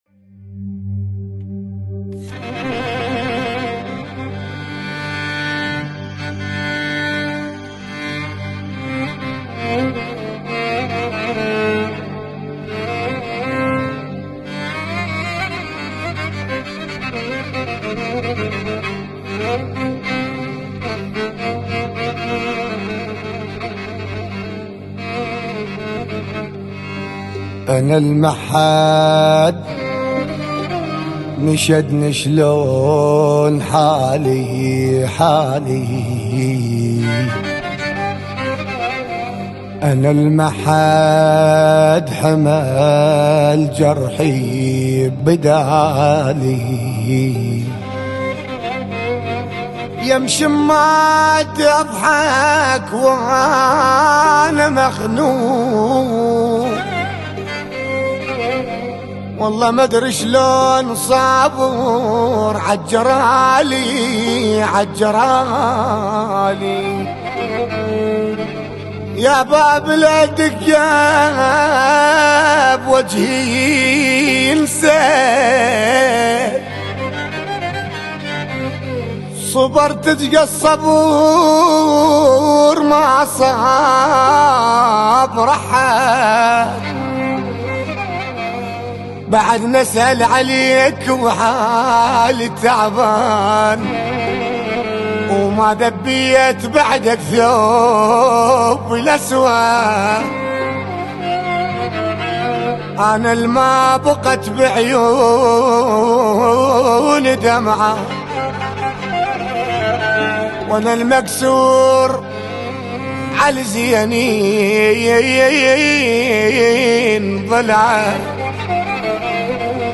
إسم القسم : اغاني عراقية